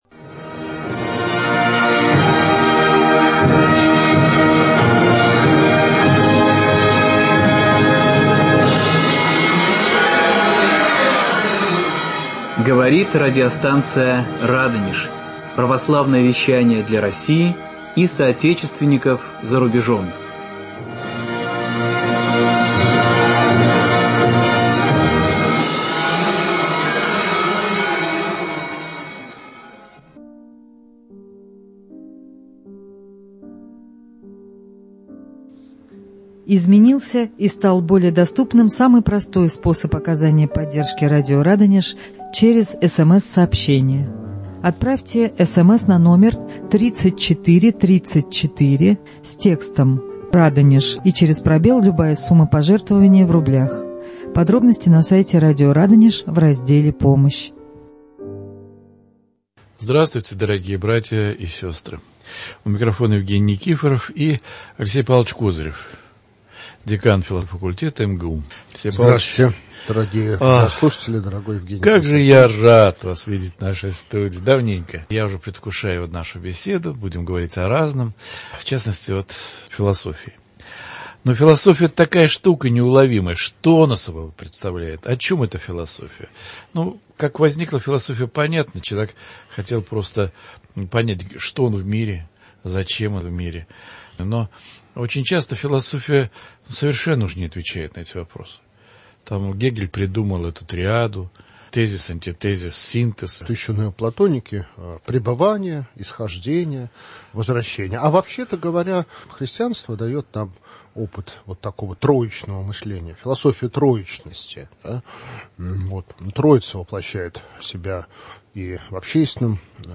беседуют в студии радиостанции "Радонеж"